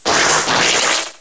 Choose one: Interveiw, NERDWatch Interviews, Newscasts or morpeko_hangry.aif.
morpeko_hangry.aif